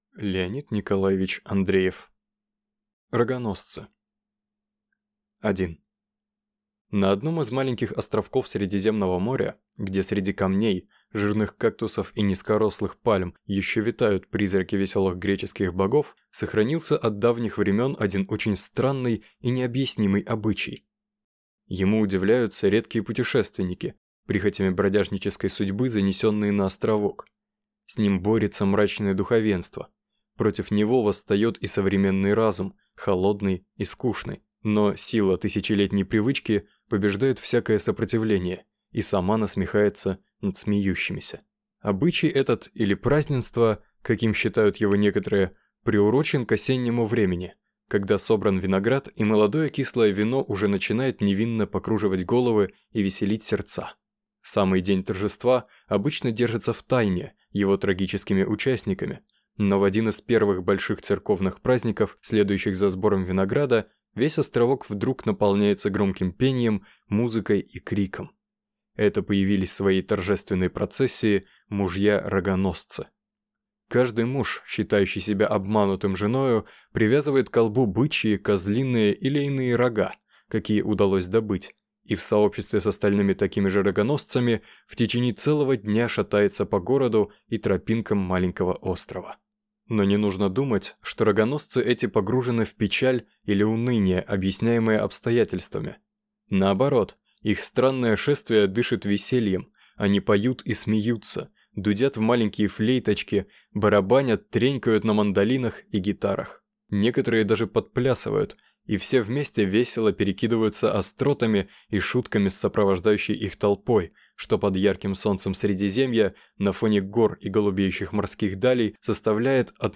Упавшая звезда (слушать аудиокнигу бесплатно) - автор Катиш Петкевич